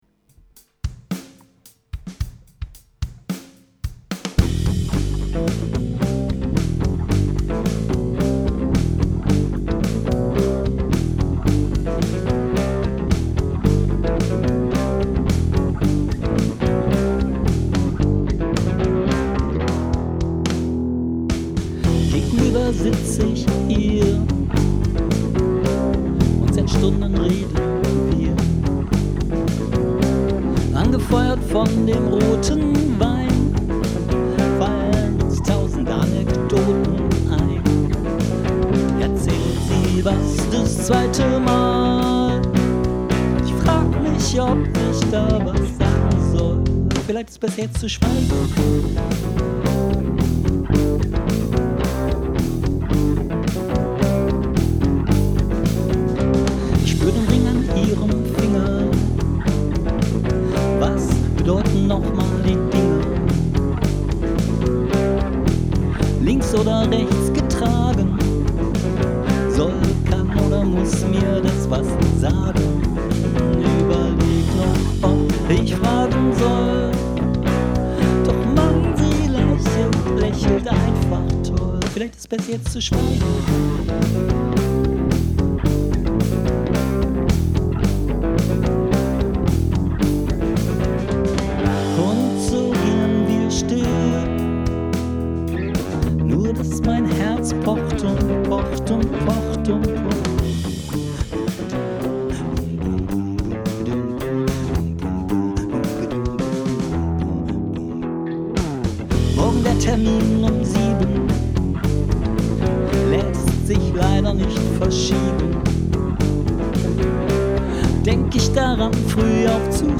Bei mir ist es ne Uptempo Nummer.